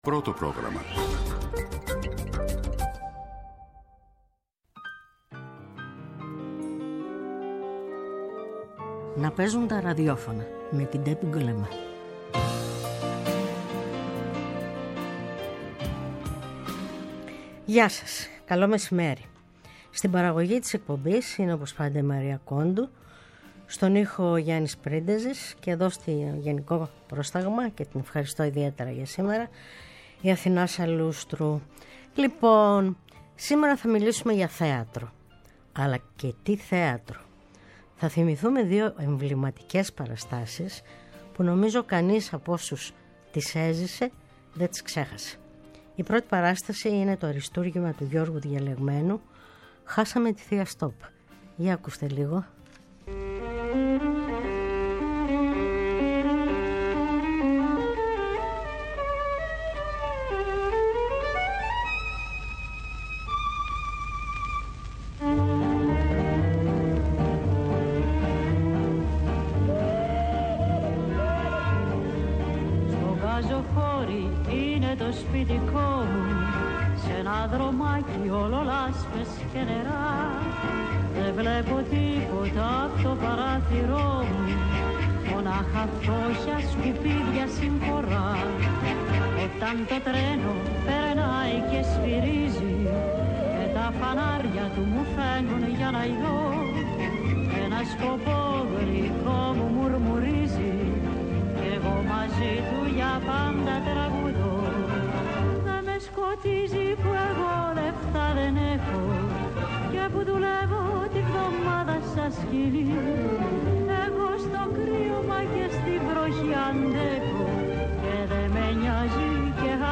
Ποιος δεν θυμάται την παράσταση, που ανέβηκε κατά τη διάρκεια της Χούντας στο «Αθήναιον», βασισμένη στο έργο του Ιάκωβου Καμπανέλλη; Στην εκπομπή θα ακούσετε ένα μικρό απόσπασμα στο οποίο ο Κώστας Καζάκος, αφηγείται το πώς ξεγελούσαν τους λογοκριτές, μπερδεύοντας τα κείμενα.
Η εκπομπή “Να παίζουν τα ραδιόφωνα” κάθε Σάββατο στη 1 το μεσημέρι φιλοξενεί στο studio ανθρώπους της Τέχνης -και όχι μόνο- σε ενδιαφέρουσες συζητήσεις με εξολογητική και χαλαρή διάθεση. ΕΡΤNEWS RADIO